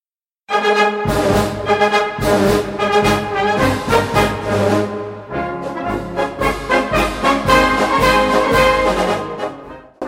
Salzburger Schützenmarsch  für die k.k. freiwilligen Schützen Dieser vom Maishofener Kapellmeister Bartholomä Berloger 1915 komponierte Marsch ist gleichzeitig der Traditionsmarsch des Militärkommandos Salzburg. Er entstand in den Schützengräben des Nassfeldes; Berloger war damals Regimentskapellmeister der k.k. freiwilligen Schützen.